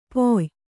♪ poy